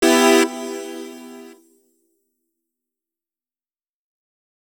Chords_E_01.wav